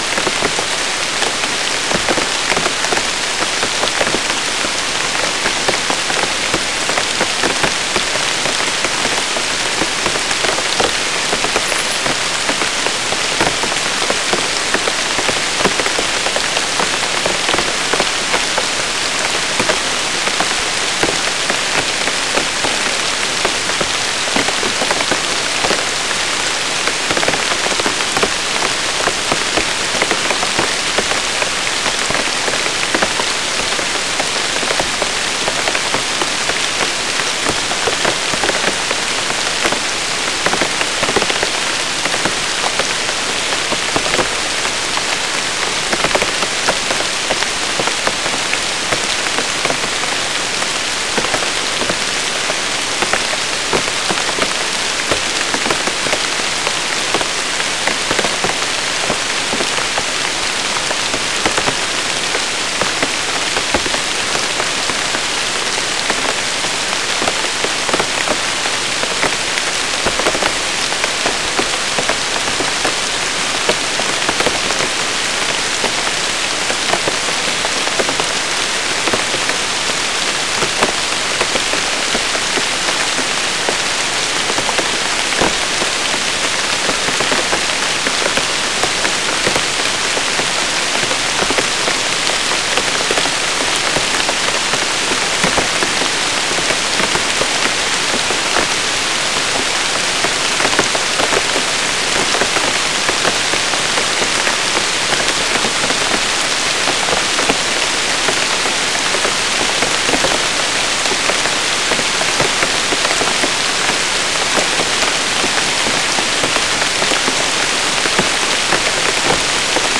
Non-specimen recording: Soundscape Recording Location: South America: Guyana: Kabocalli: 3
Recorder: SM3